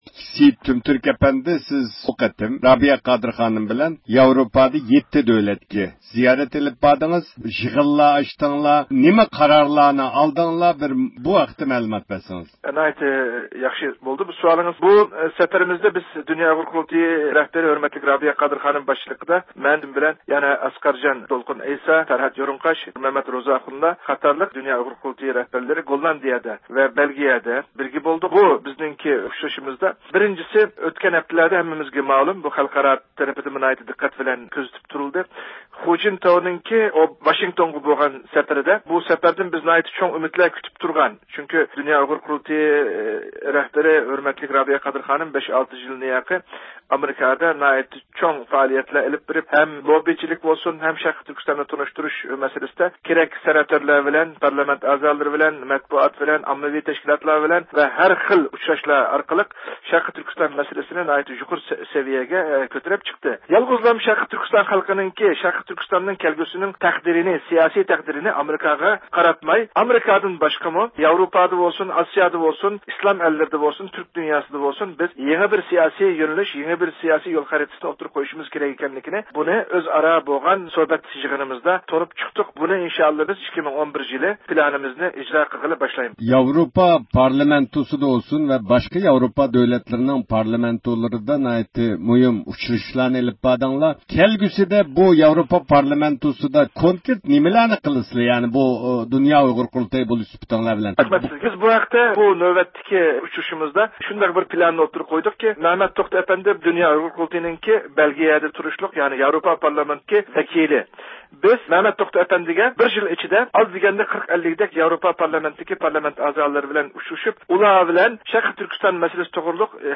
بىز بۇ يىغىن ۋە د ئۇ ق رەھبەرلىرىنىڭ ياۋروپادىكى 7 دۆلەتتە ئېلىپ بارغان زىيارىتى ھەققىدە خۇلاسە پىكىرلىرىنى ئېلىش ئۈچۈن د ئۇ ق رەھبەرلىرى بىلەن سۆھبەت ئېلىپ باردۇق.